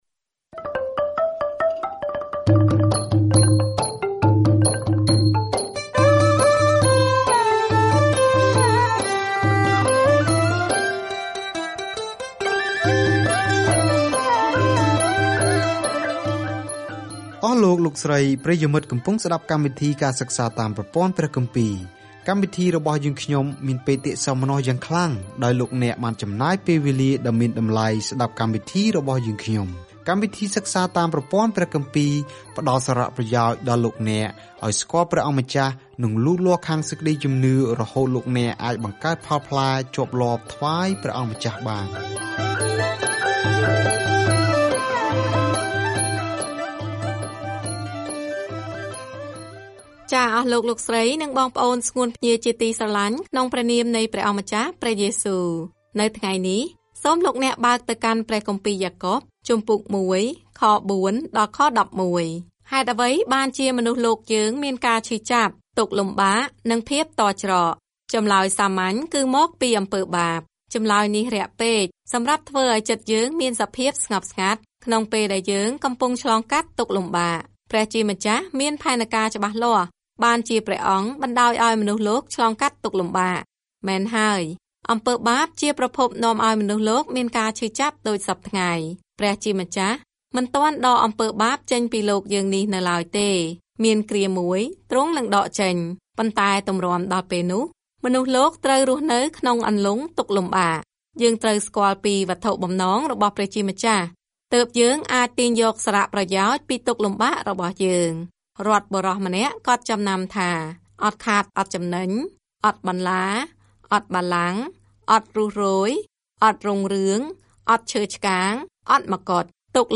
ការធ្វើដំណើរជារៀងរាល់ថ្ងៃតាមរយៈយ៉ាកុប នៅពេលអ្នកស្តាប់ការសិក្សាជាសំឡេង ហើយអានខគម្ពីរដែលជ្រើសរើសពីព្រះបន្ទូលរបស់ព្រះ។